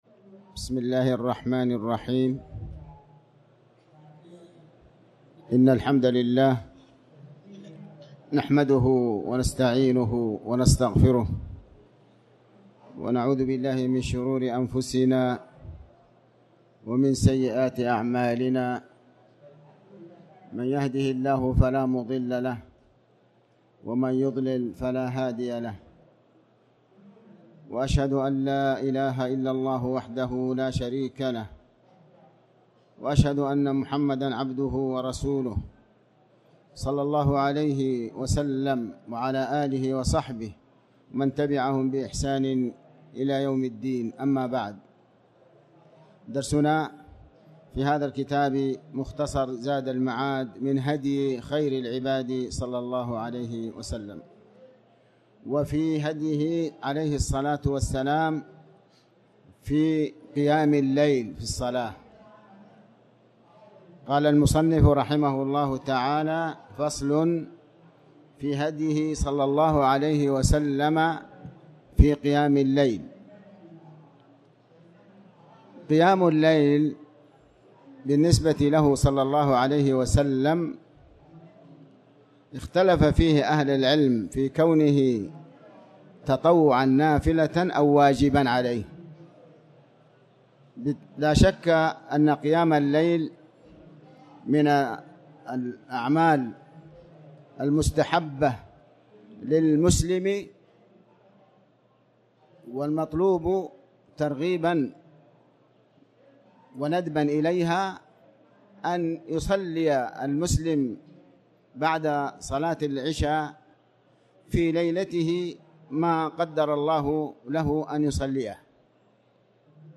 تاريخ النشر ٢٠ ربيع الأول ١٤٤٠ هـ المكان: المسجد الحرام الشيخ: علي بن عباس الحكمي علي بن عباس الحكمي فصل في قيام الليل